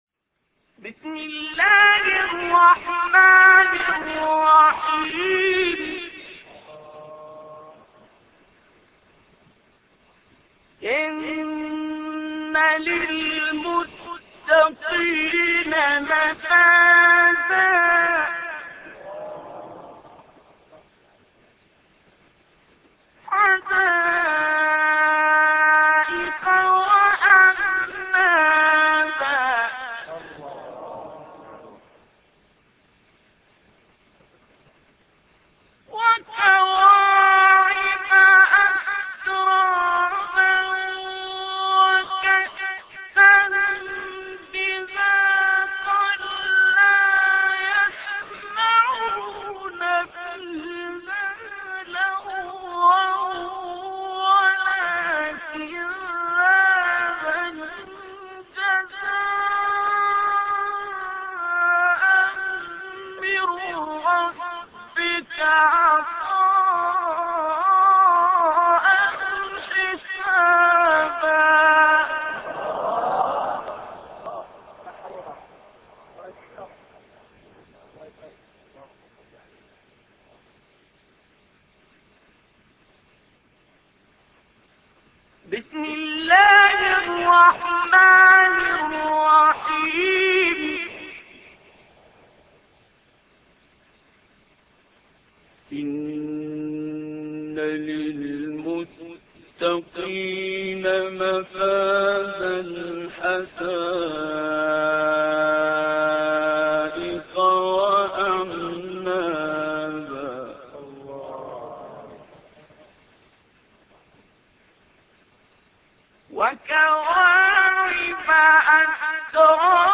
تلاوت آیاتی از سوره نبأ توسط استاد عبدالباسط
تهران - الکوثر : در ادامه تلاوت آیات 31 تا آخر سوره نبأ را با صدای مرحوم عبدالباسط محمد عبدالصمد می شنوید.